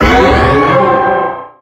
Cri de Méga-Dracaufeu Y dans Pokémon HOME.
Cri_0006_Méga_Y_HOME.ogg